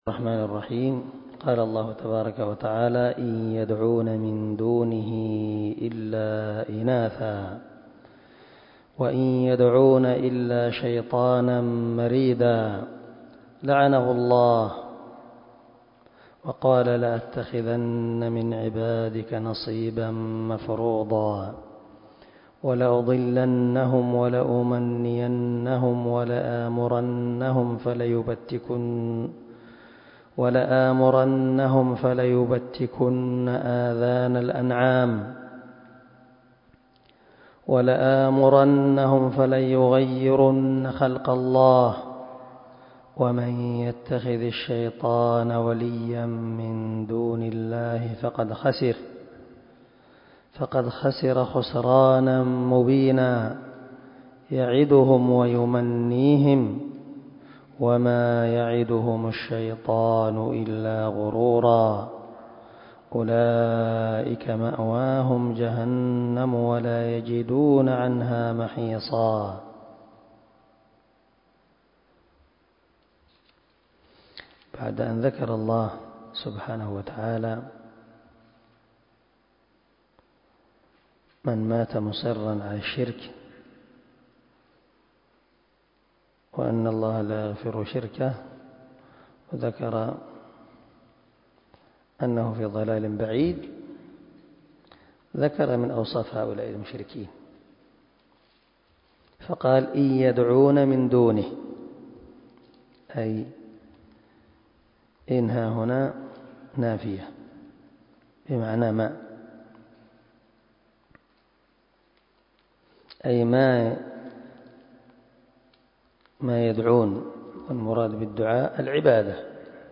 308الدرس 76 تفسير آية ( 117 - 121 )من سورة النساء من تفسير القران الكريم مع قراءة لتفسير السعدي